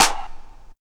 • Clap Sound Clip G# Key 09.wav
Royality free clap sample - kick tuned to the G# note. Loudest frequency: 3187Hz
clap-sound-clip-g-sharp-key-09-UQj.wav